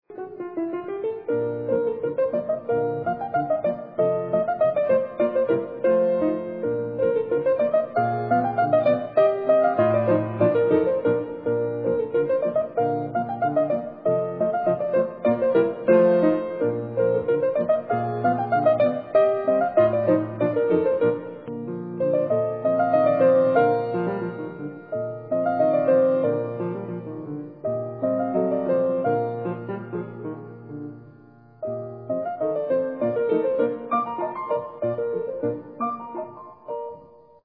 Theme